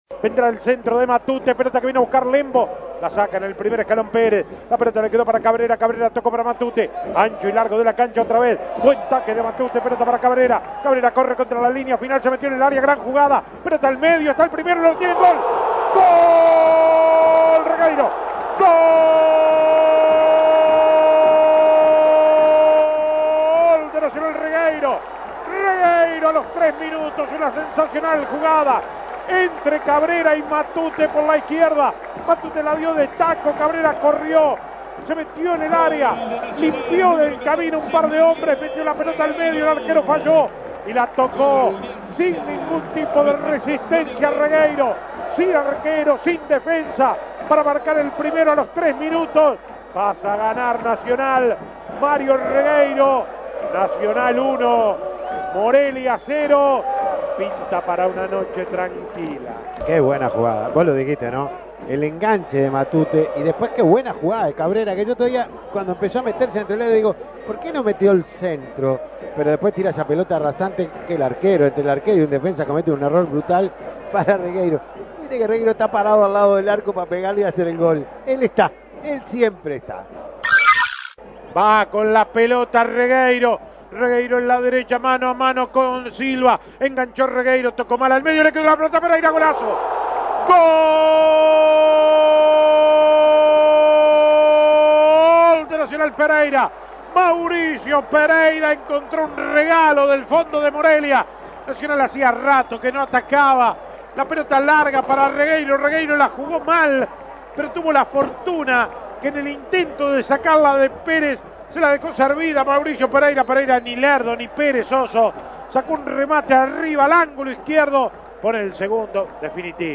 Goles y comentarios Escuche los goles del partido Nacional - Morelia Imprimir A- A A+ Escuche los goles de Mario Regueiro y Mauricio Pereyra en la victoria de Nacional 2-0 ante Morelia.